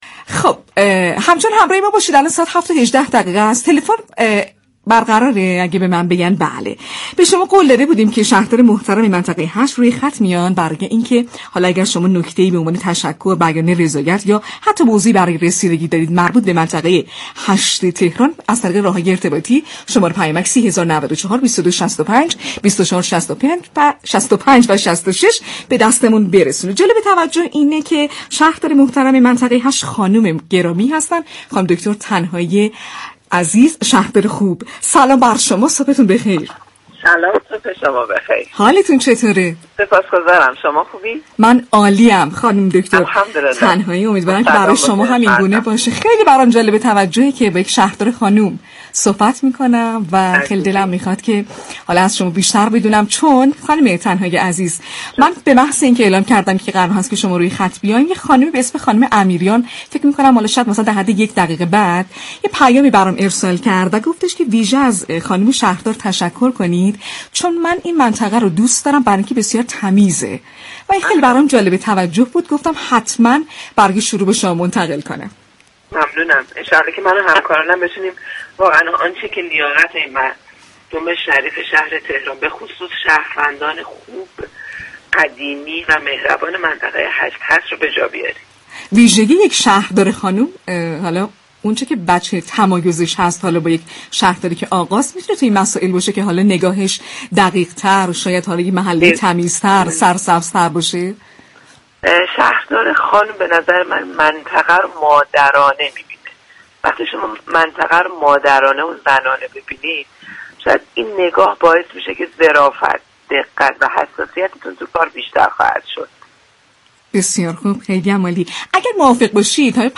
دریافت فایل به گزارش پایگاه اطلاع رسانی رادیو تهران، فاطمه تنهایی شهردار منطقه هشت تهران در گفت و گو با «شهر آفتاب» اظهار داشت: شهردار خانم منطقه را مادرانه و زنانه مدیریت می‌كند این نگاه باعث دقت و حساسیت در اجرای كارها می‌شود.